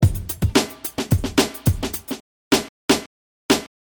Aim for a nice, clean hit without any strange background noises.